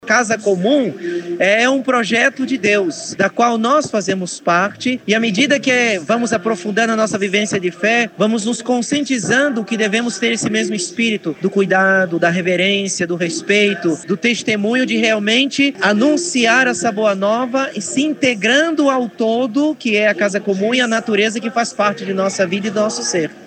A Santa Missa foi campal, presidida pelo bispo auxiliar de Manaus, Dom Samuel Lima, e concelebrada por alguns padres do Setor Centro Histórico e padres Salesianos.